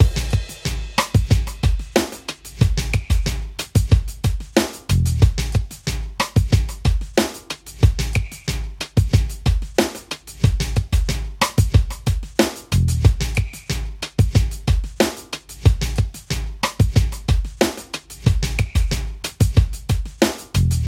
描述：主要的旋律是由我的电子鼓组演奏和录制的。我所有的循环都是用真实的节拍和旋律制作的。
标签： 92 bpm Electro Loops Drum Loops 3.51 MB wav Key : Unknown
声道立体声